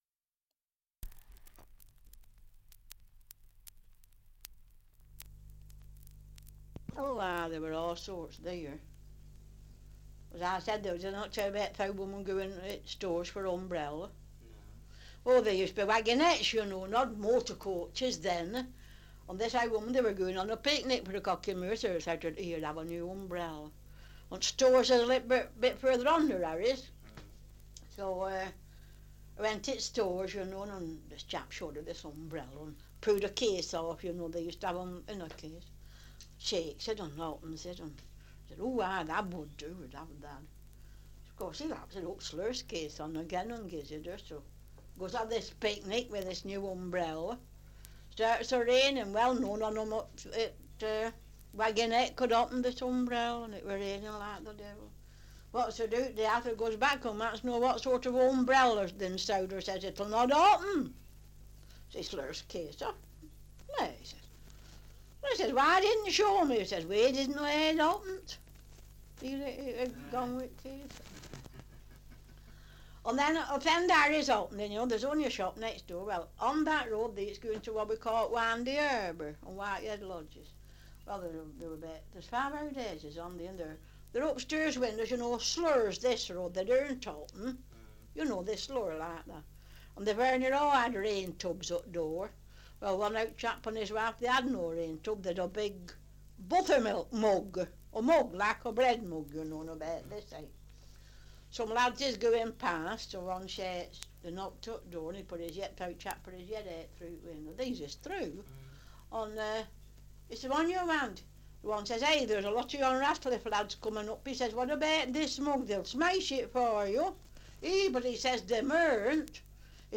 Survey of English Dialects recording in Harwood, Lancashire
78 r.p.m., cellulose nitrate on aluminium